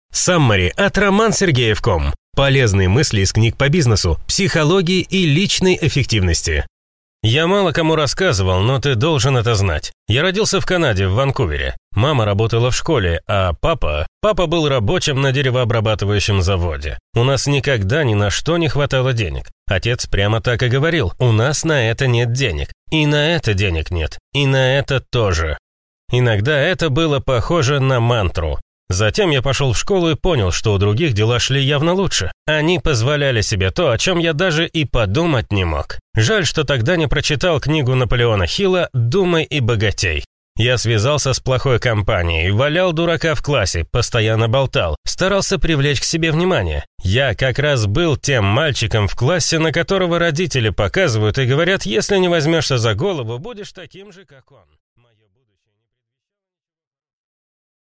Аудиокнига Саммари на книгу «Достижение Максимума». Брайан Трейси | Библиотека аудиокниг